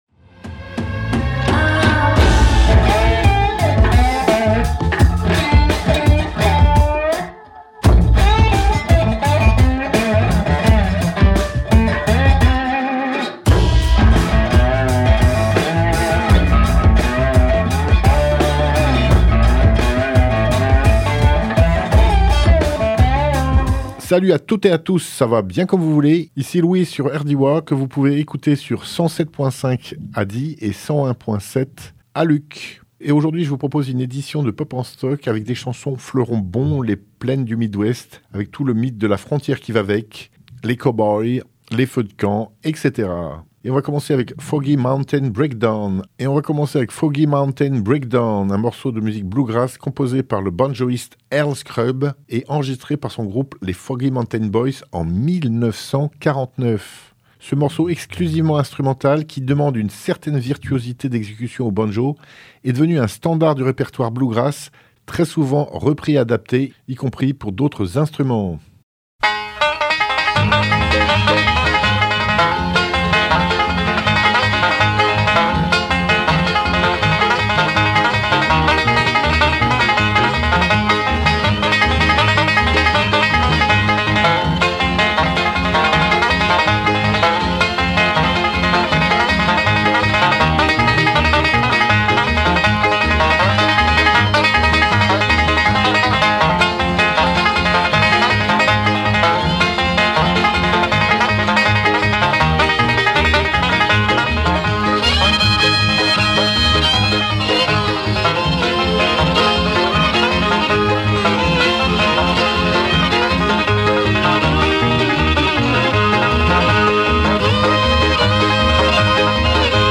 country , folk